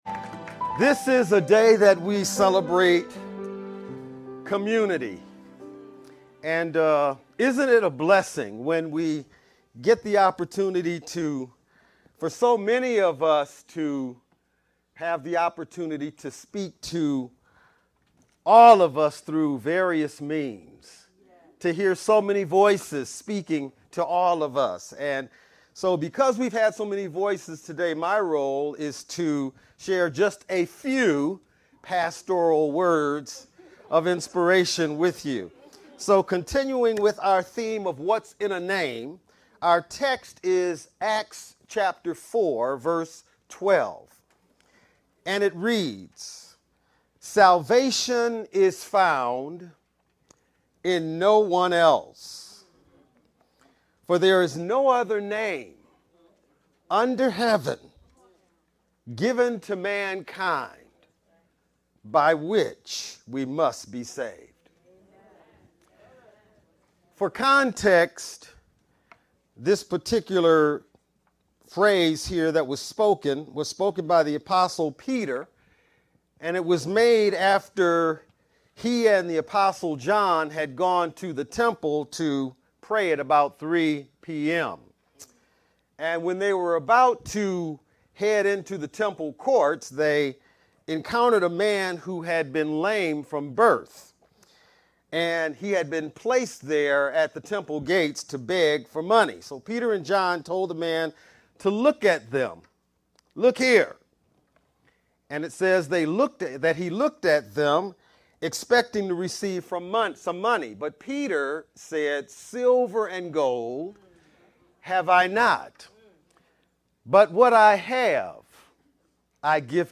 Black-History-Program-23-sermon-only-Mp3.mp3